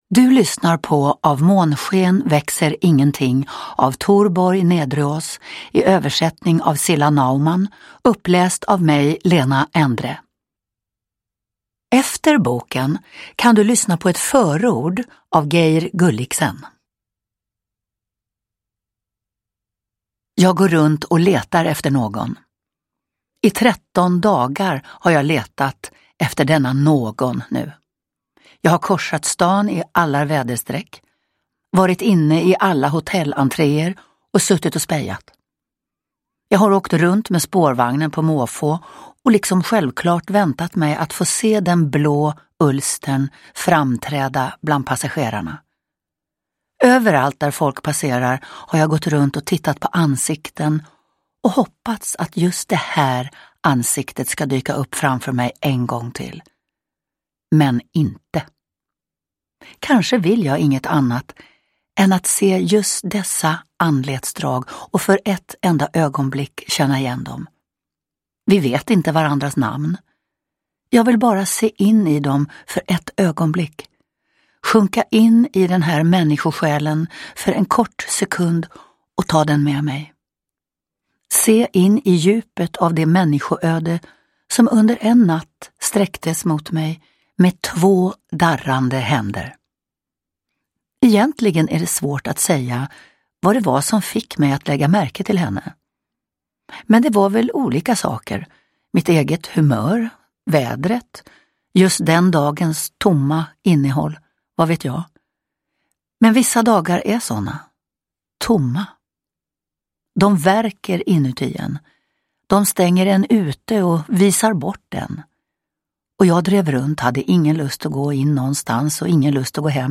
Av månsken växer ingenting – Ljudbok
Uppläsare: Lena Endre